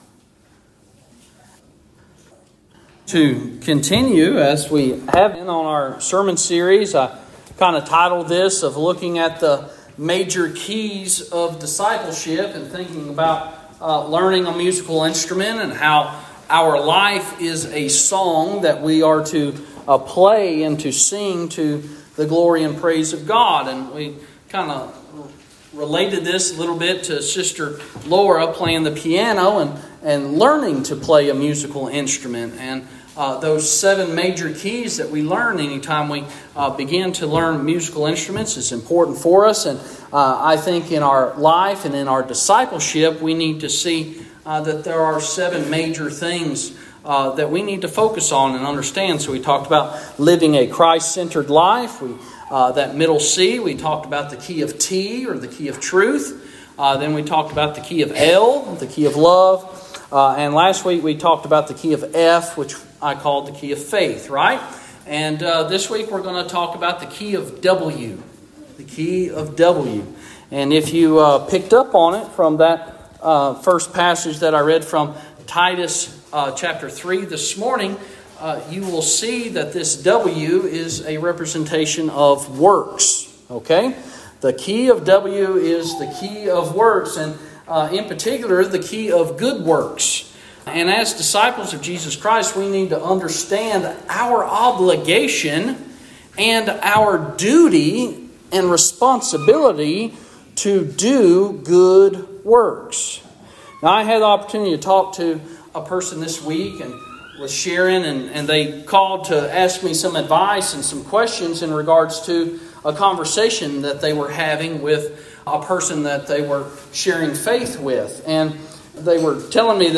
Today's Sermon